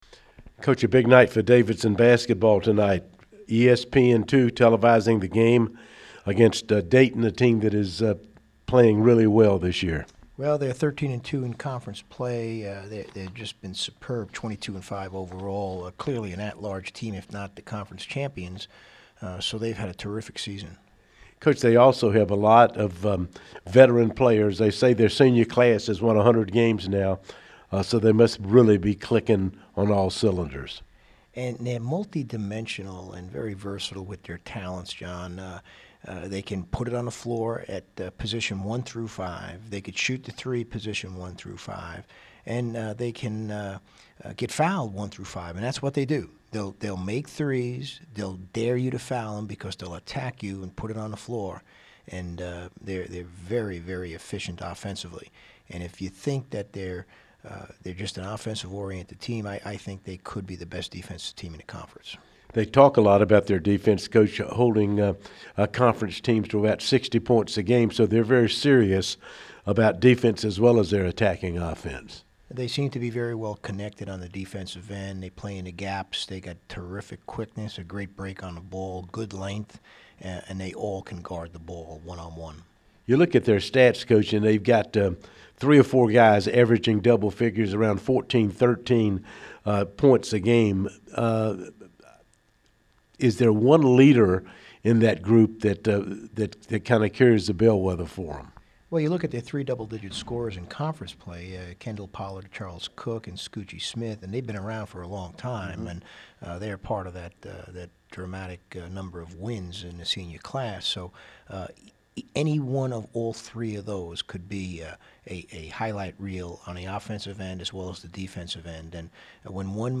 Pregame Radio Interview